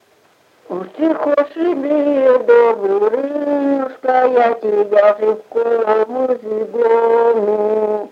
Упрощение консонансов с выпадением одного из элементов консонанса